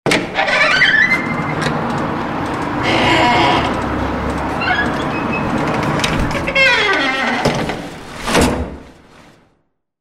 Door creaking sound ringtone free download
Sound Effects